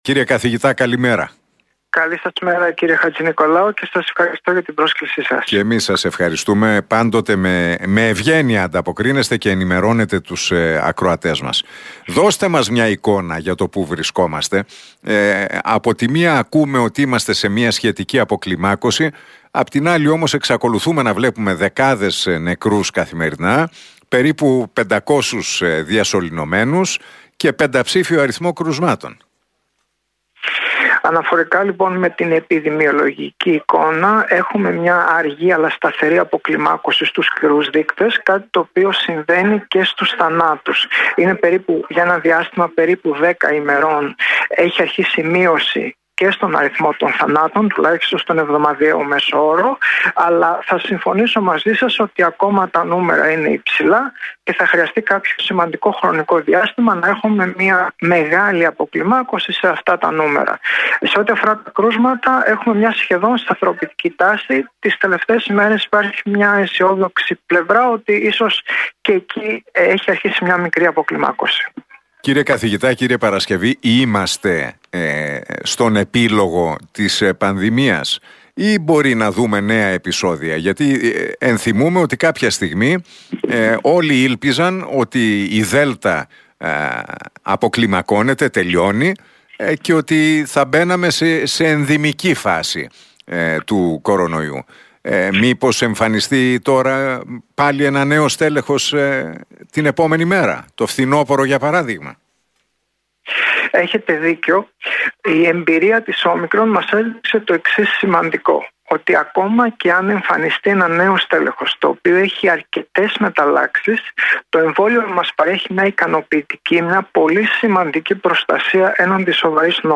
Ο Δημήτρης Παρασκευής,  αναπληρωτής καθηγητής Επιδημιολογίας -Προληπτικής Ιατρικής της Ιατρικής Σχολής ΕΚΠΑ και μέλος της επιτροπής εμπειρογνωμόνων του υπουργείου Υγείας, μιλώντας στον Realfm 97,8 και στην εκπομπή του Νίκου Χατζηνικολάου δήλωσε ότι “έχουμε μια αργή αλλά σταδιακή αποκλιμάκωση στους σκληρούς δείκτες, κάτι που συμβαίνει και στους θανάτους.